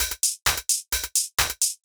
130_HH+clap_2.wav